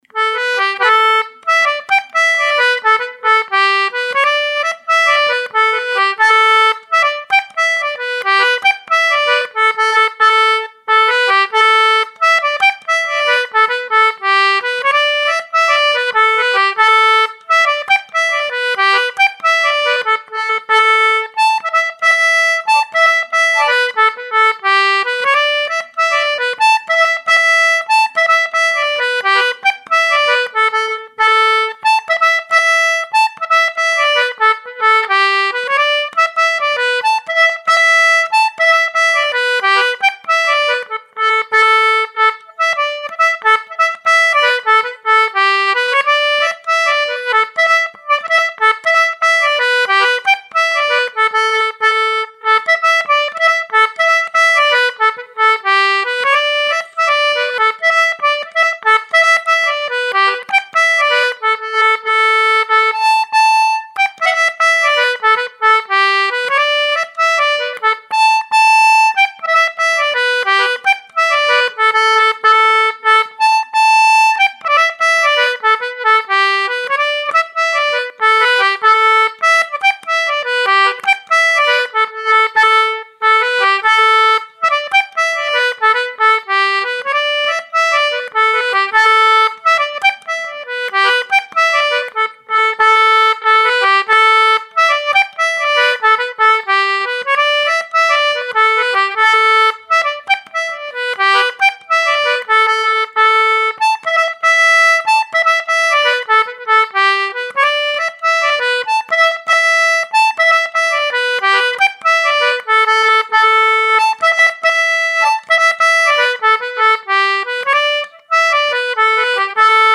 Recorded Edinburgh, 12 May 2022.